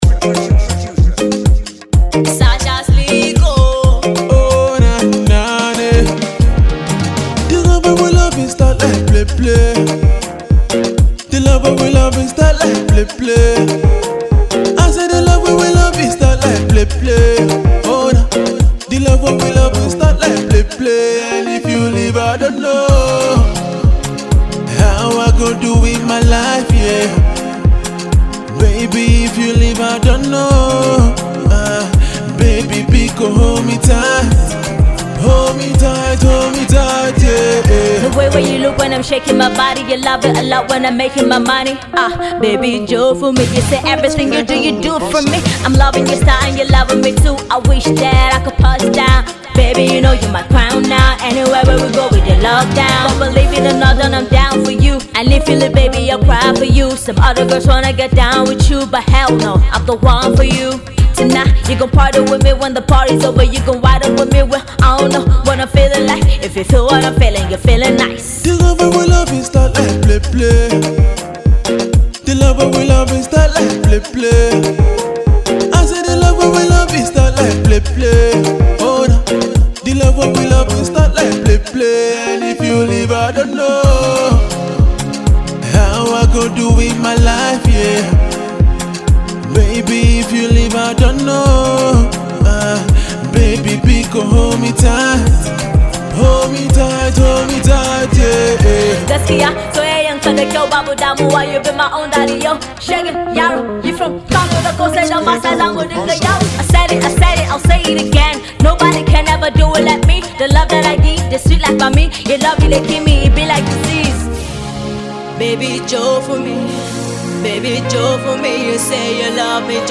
female Emcee